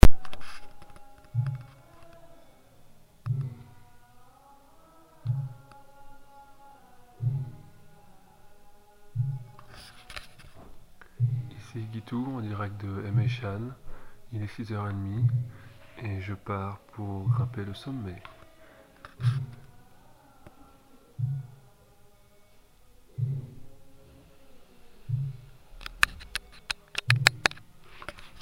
depart pour le sommet.MP3